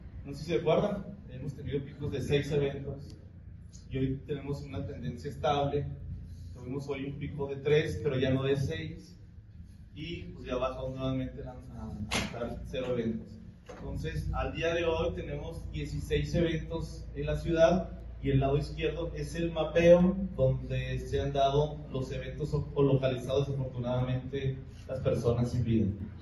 El comisario Julio César Salas, expuso en rueda de prensa que el martes 17, se registraron tres asesinatos, que lo colocó como el día mas violento pues durante los demás se promedió uno cada 24 horas. Dio a conocer que se detuvieron a 76 personas tanto en las labores preventivas de la Policía Municipal como en los operativos coordinados con las corporaciones estatales y federales.